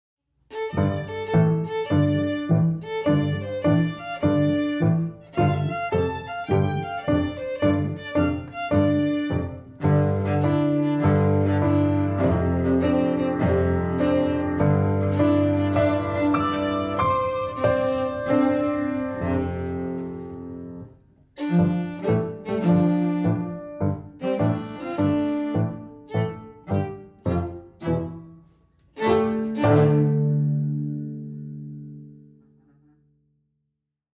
In all the pieces, Staff 1 carries the Melody.